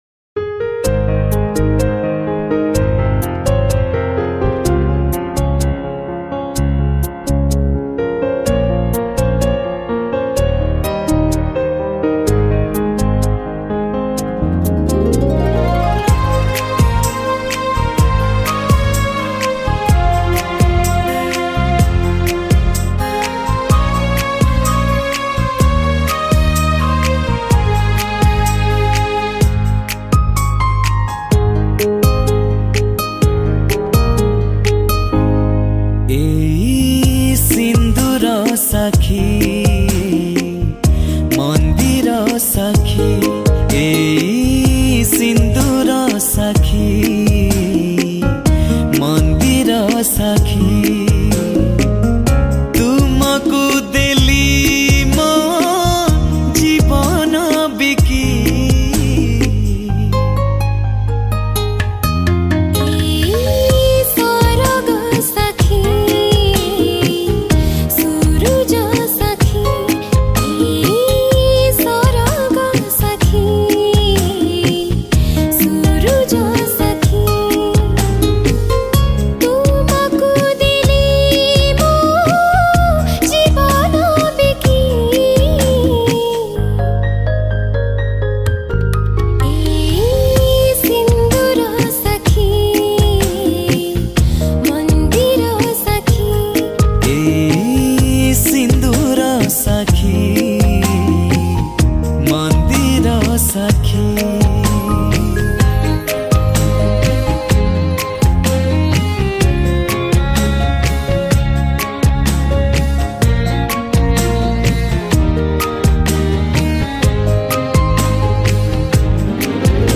Odia Cover Song